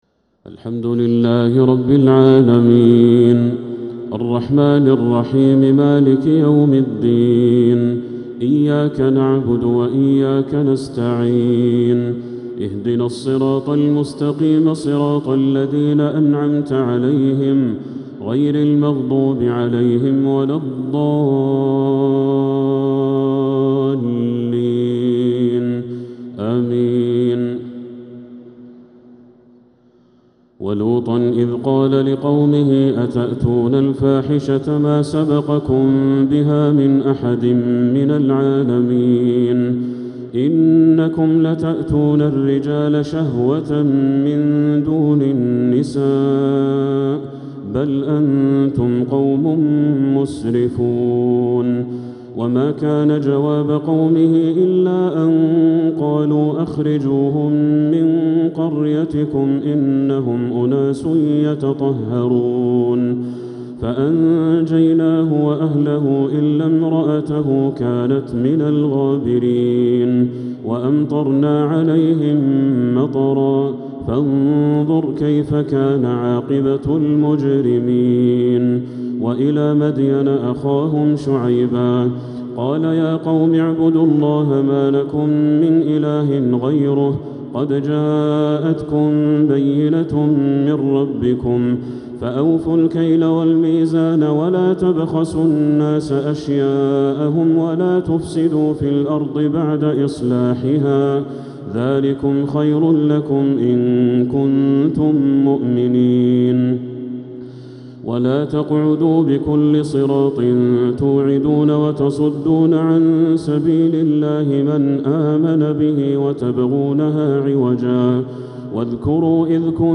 تراويح ليلة 11 رمضان 1447هـ من سورة الأعراف (80-141) | Taraweeh 11th niqht Ramadan Surat Al-A’raf 1447H > تراويح الحرم المكي عام 1447 🕋 > التراويح - تلاوات الحرمين